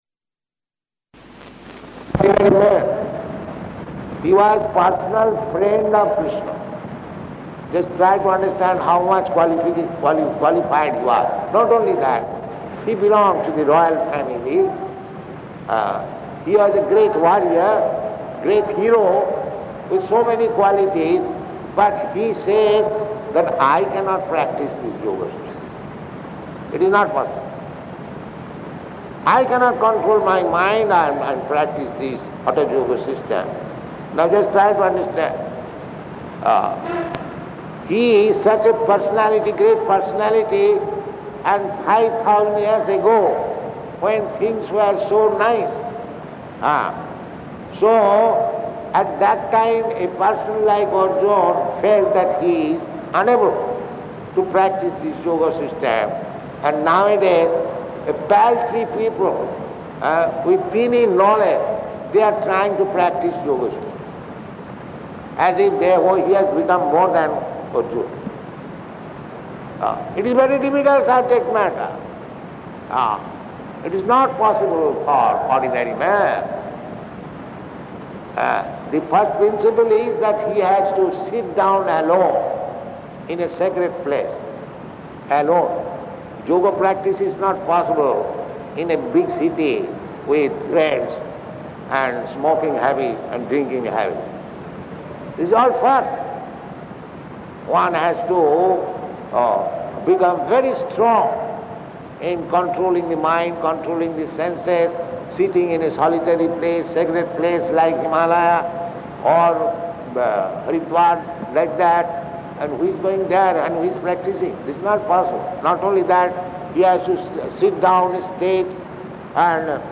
Lecture
Lecture --:-- --:-- Type: Lectures and Addresses Dated: March 1st 1973 Location: Jakarta Audio file: 730301LE.JKT.mp3 Prabhupāda: ...he was personal friend of Kṛṣṇa.